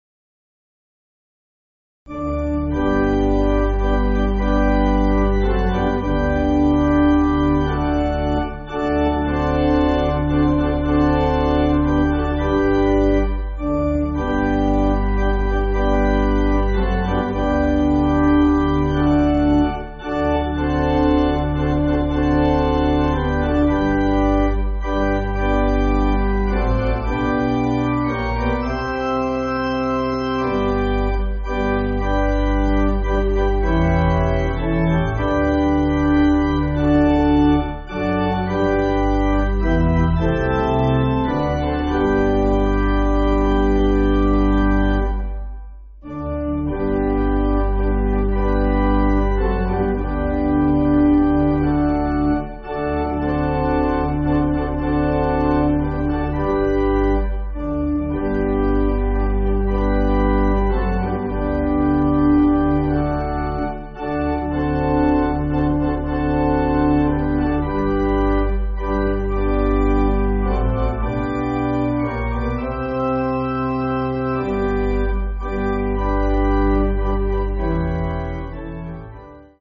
Organ
(CM)   3/G